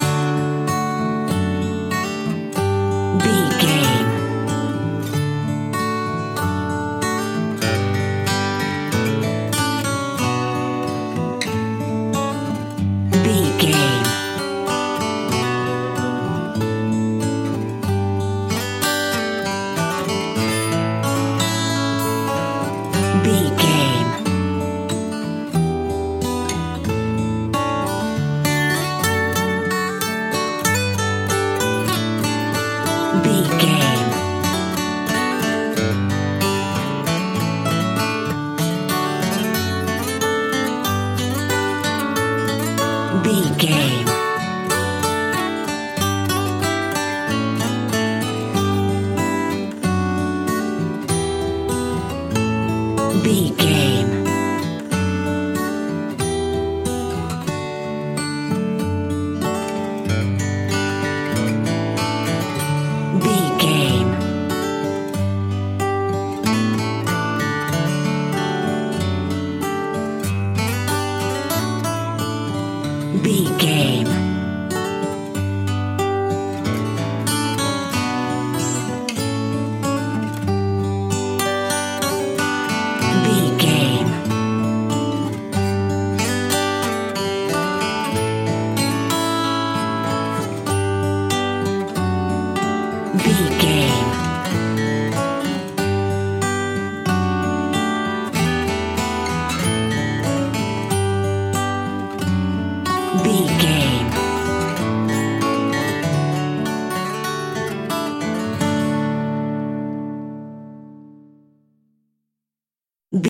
Ionian/Major
Slow
acoustic guitar
bass guitar